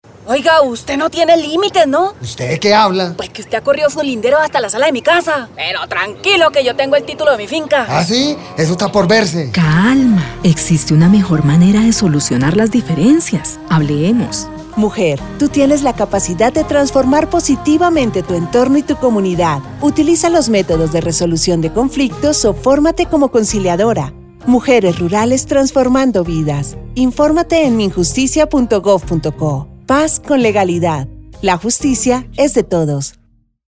Cuña 1 LINDEROS CAMPAÑA MUJERES RURALES TRANSFORMANDO VIDAS.mp3